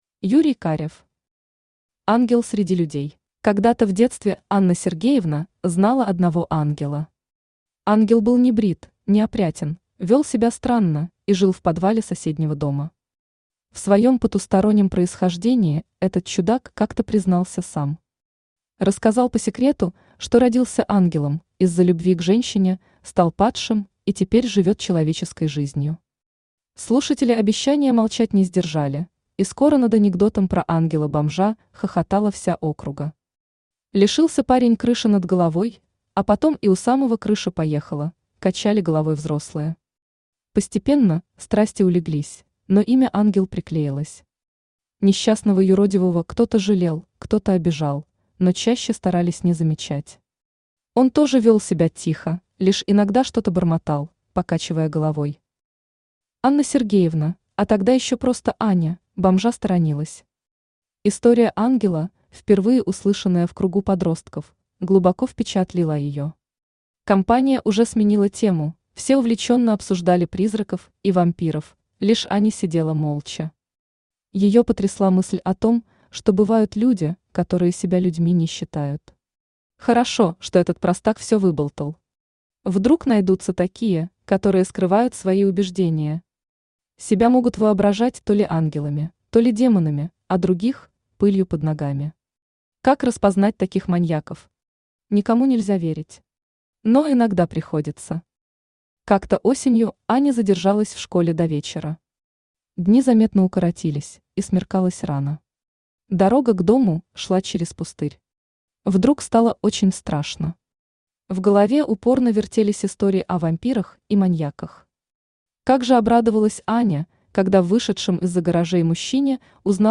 Аудиокнига Ангел среди людей | Библиотека аудиокниг
Aудиокнига Ангел среди людей Автор Юрий Олегович Карев Читает аудиокнигу Авточтец ЛитРес.